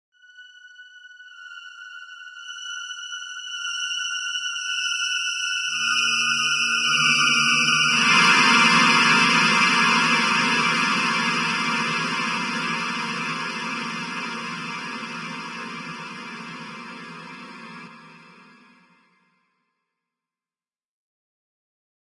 电影原声带弦乐 " 三个半音符Stike
描述：弦乐合奏的合成短篇。长衰减，宽立体声图像。为帮助你在业余电影中制造紧张气氛而制作。用LMMS内的ZynAddSubFX合成（96kHz/32位）。用Audacity进行编辑和归一化，然后保存为24位的FLAC.
标签： 合成 合奏 音乐 电影配乐 弦乐 电影 谐波 光滑 电影 音响
声道立体声